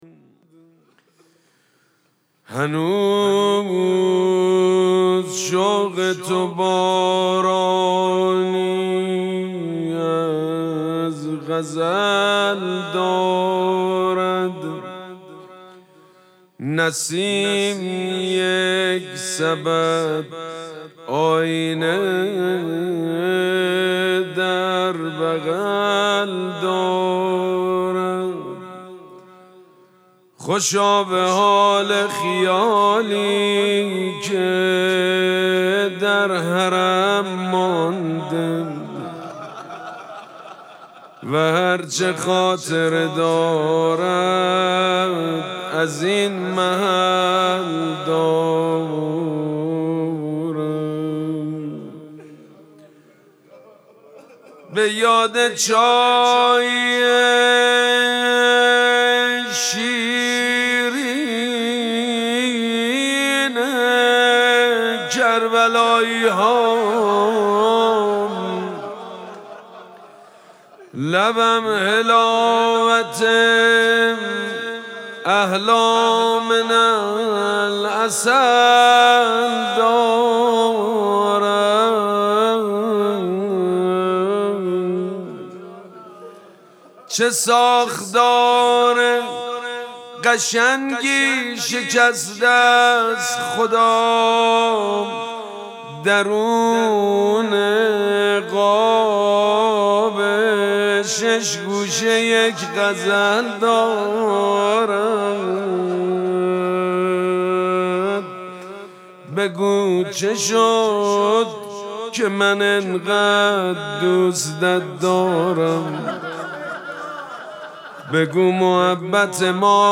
مراسم مناجات شب سوم ماه مبارک رمضان
مناجات